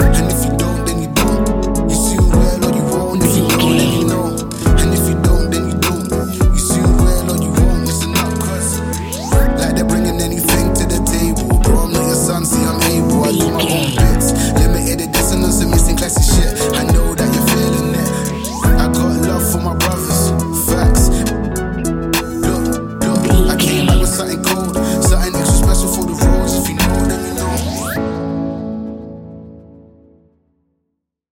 Ionian/Major
chill out
laid back
Lounge
sparse
new age
chilled electronica
ambient
atmospheric
morphing